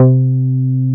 R MOOG C4P.wav